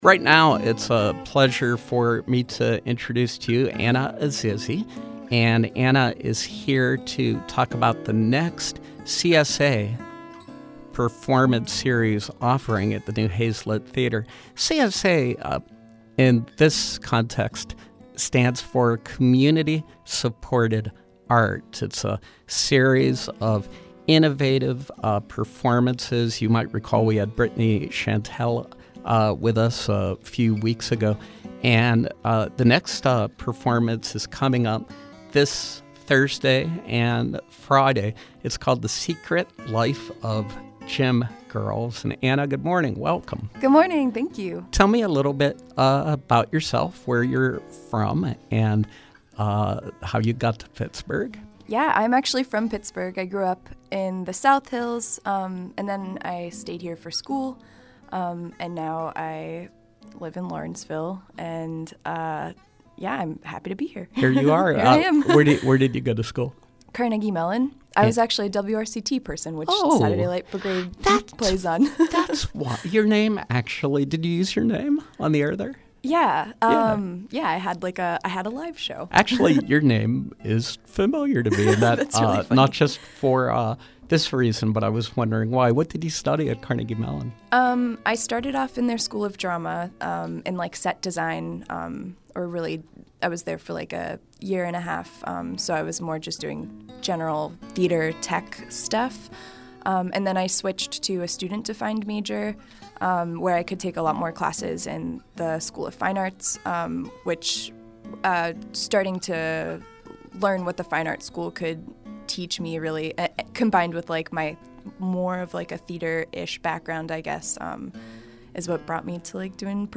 In Studio Pop-up: CSA Performance Series
Interviews